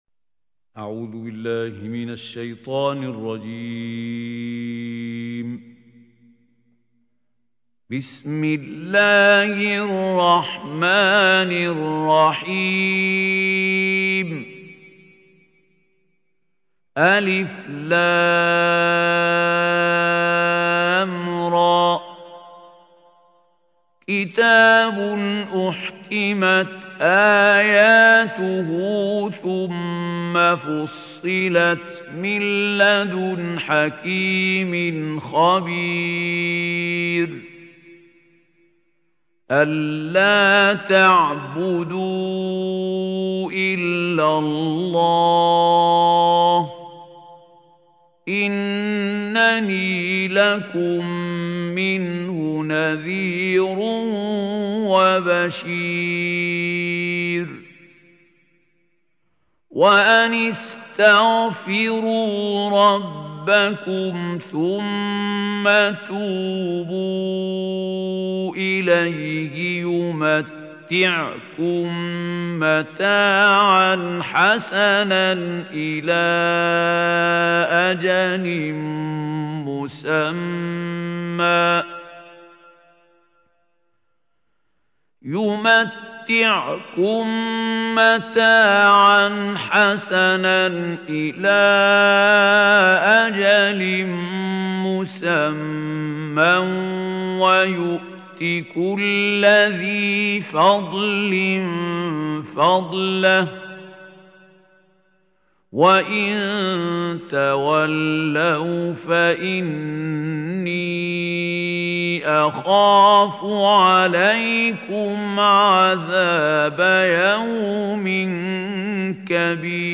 سورة هود | القارئ محمود خليل الحصري